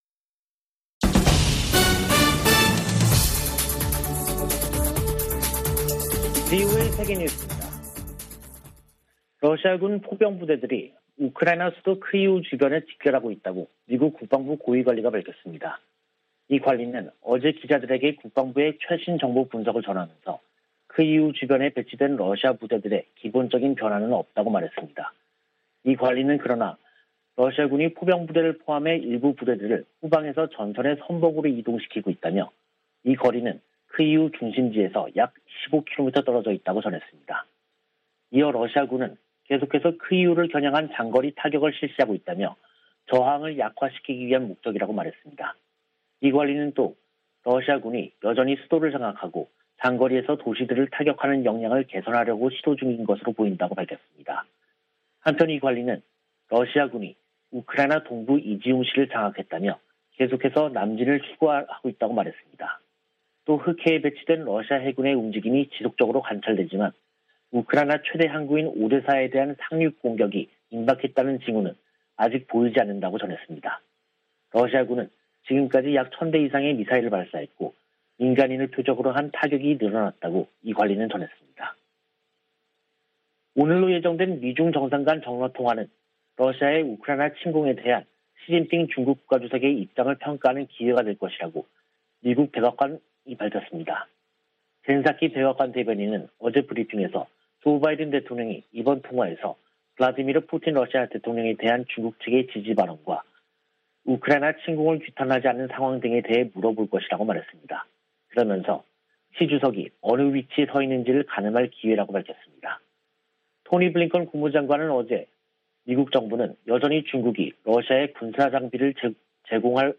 VOA 한국어 간판 뉴스 프로그램 '뉴스 투데이', 2022년 3월 18일 3부 방송입니다. 백악관은 북한의 미사일 발사를 거듭 규탄하고 한일 양국 방어 의지를 재확인했습니다. 한국의 윤석열 차기 정부가 현 정부보다 미국의 정책에 더 부합하는 외교정책을 추구할 것으로 미 의회조사국이 분석했습니다. 토마스 오헤아 퀸타나 유엔 북한인권특별보고관은 지난 6년 동안 북한 인권 상황이 더욱 악화했다고 평가했습니다.